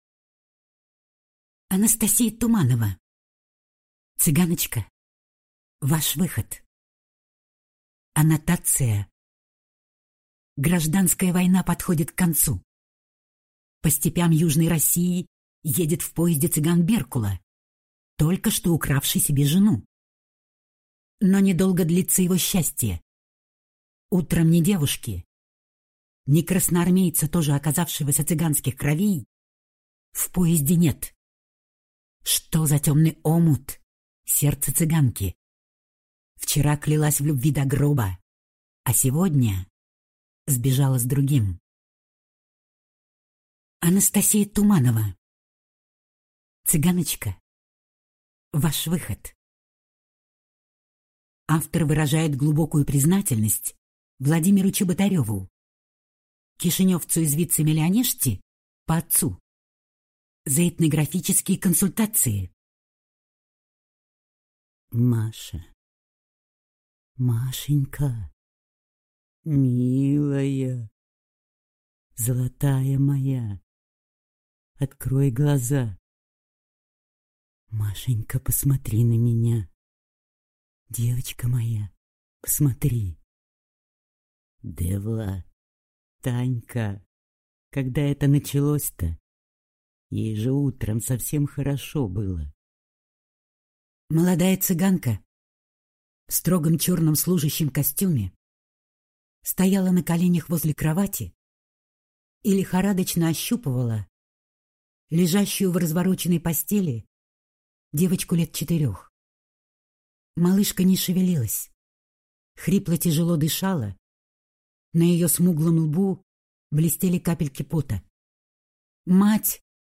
Аудиокнига Цыганочка, ваш выход!